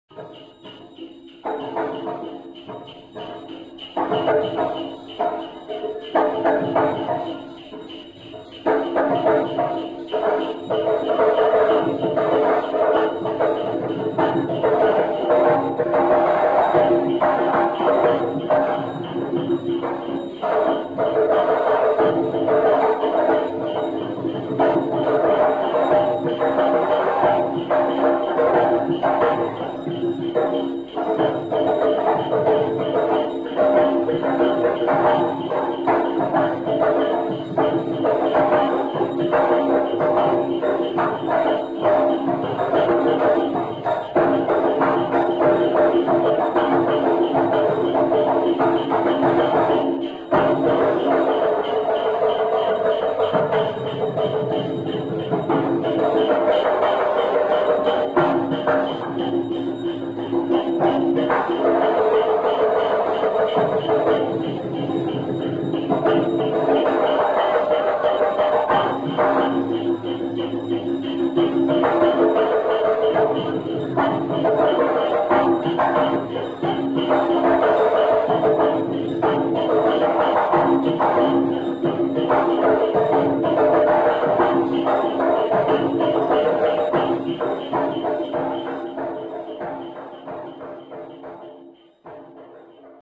Glawng Khaek
The drums are used in pairs and are of different pitches. The higher-toned drum is referred to as “male," and the lower-toned drum, as “female."
A complex rhythmic line is created by the intermingling of the two drums.